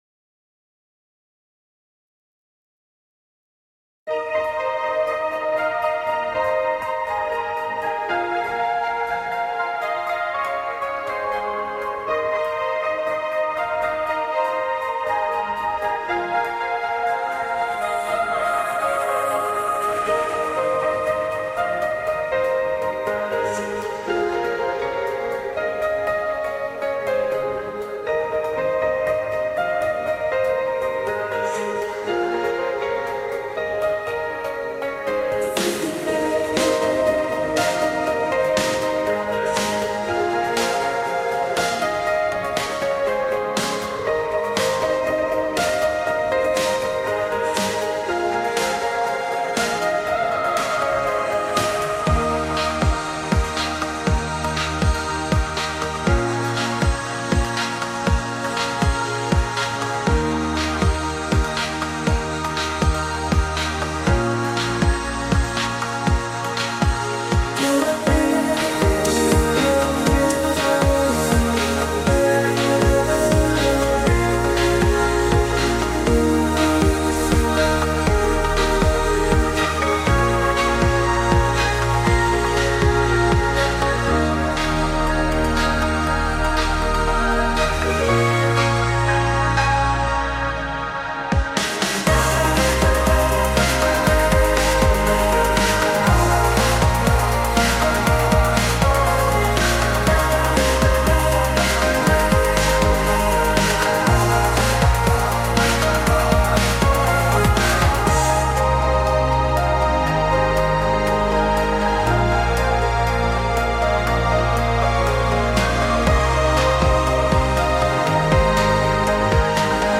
ListenWatch on YouTube  Synthwave, dreampop, Asian pop